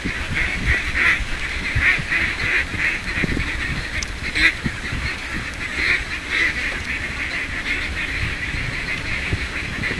Звуки уток
Утиная стая